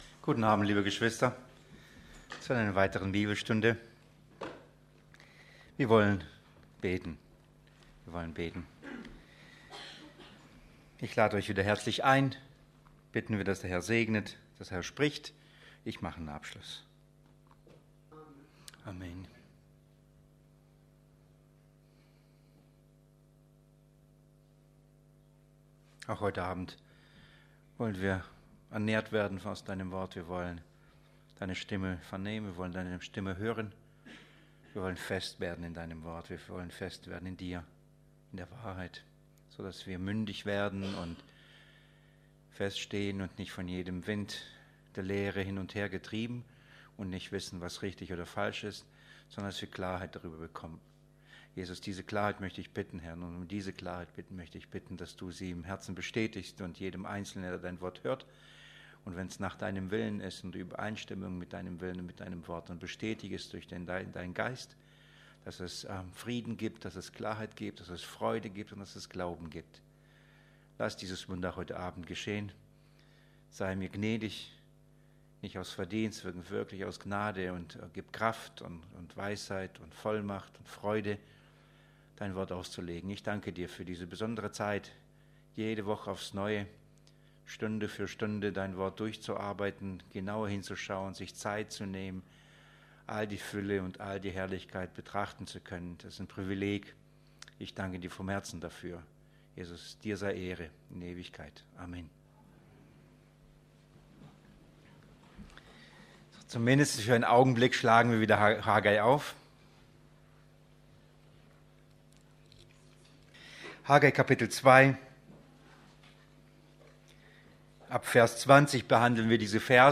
Bibelstunde Bettringen: 22.06.2016